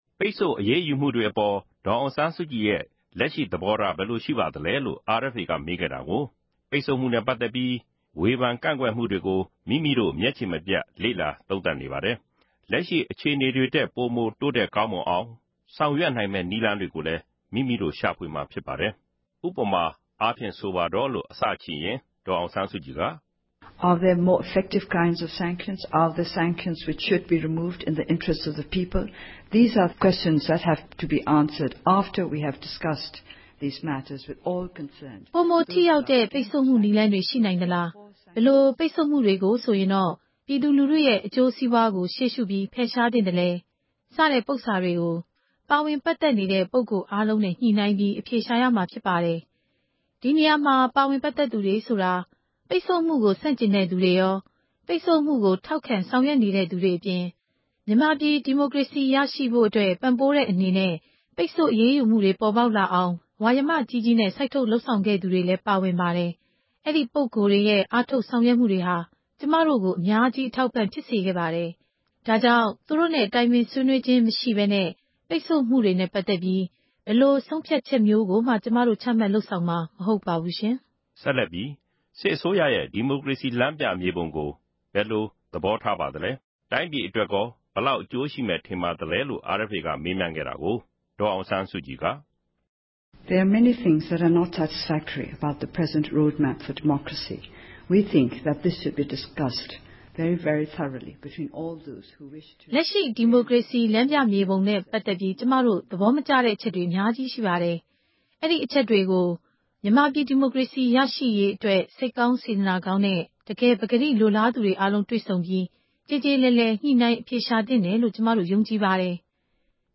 ဒေါ်အောင်ဆန်းစုကြည်နှင့် ဆက်သွယ်မေးမြန်းချက် (၁)